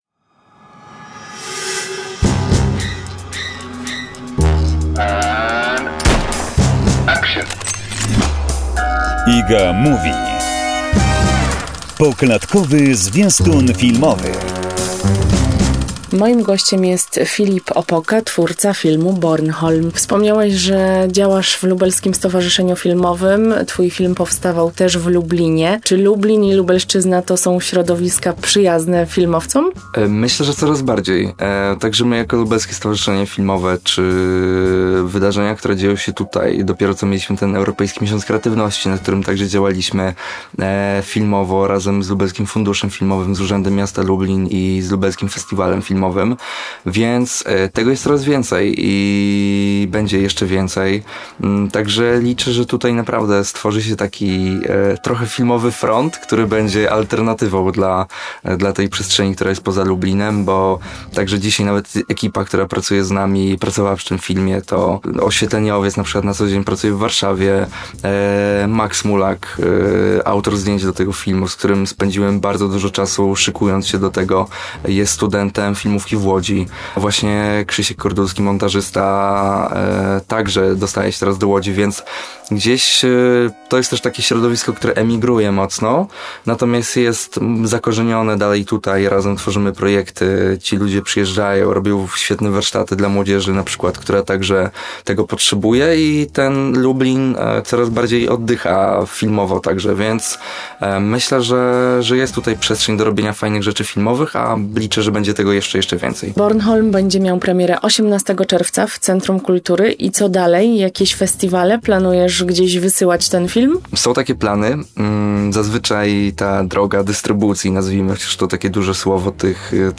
Kolejna odsłona rozmowy